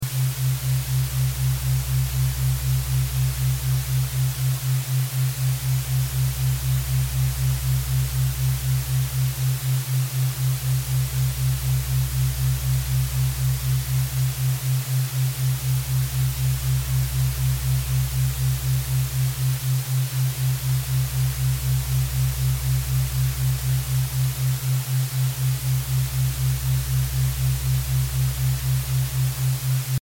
128 Hz Healing Frequency | sound effects free download
Hemi-Sync Soundscape for Grounding & Heart Chakra Balance
This 30-second Hemi-Sync soundscape blends Monroe Institute-style binaural beats with soothing ambient textures to help you: